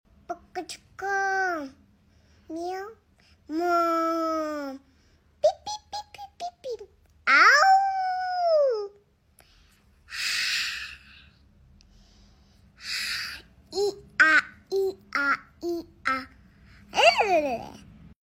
Adorable Animal sounds|| Cute baby sound effects free download